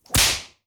Annoyed.wav